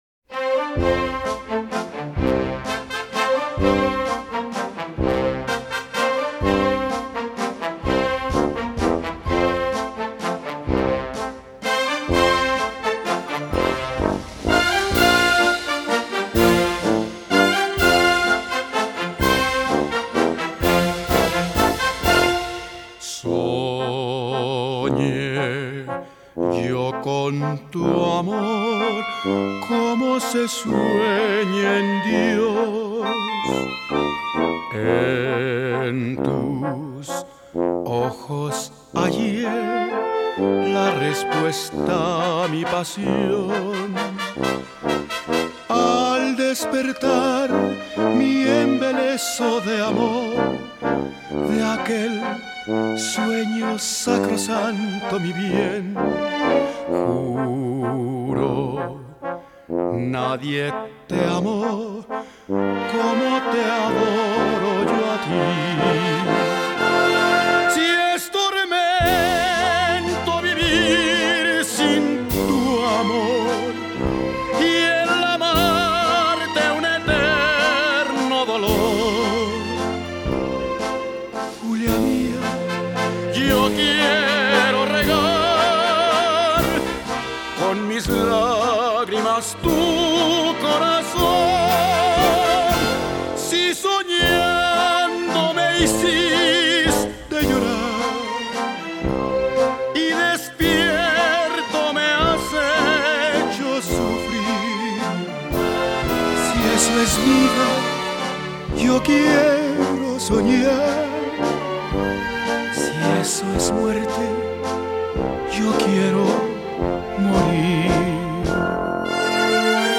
y con banda: